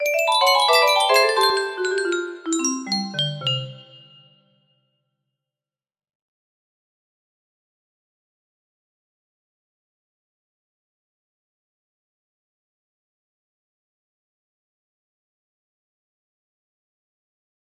Unknown Artist - Untitled music box melody
Yay! It looks like this melody can be played offline on a 30 note paper strip music box!